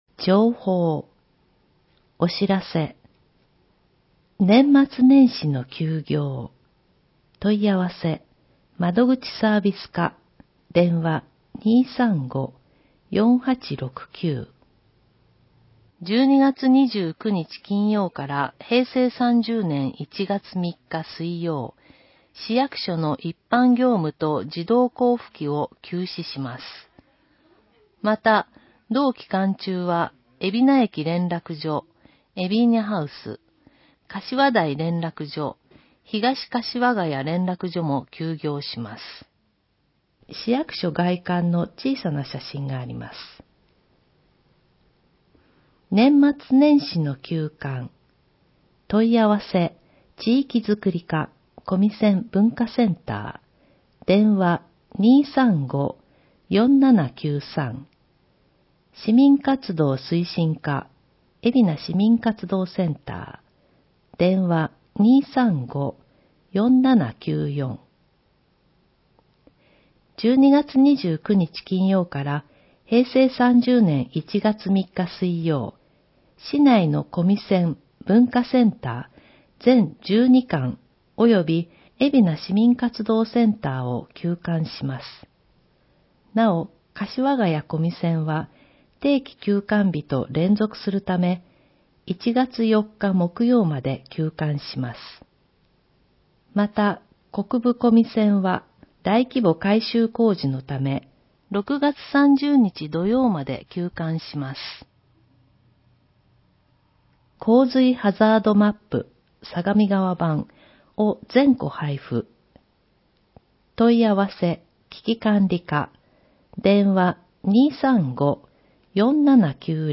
広報えびな 平成29年12月15日号（電子ブック） （外部リンク） PDF・音声版 ※音声版は、音声訳ボランティア「矢ぐるまの会」の協力により、同会が視覚障がい者の方のために作成したものを登載しています。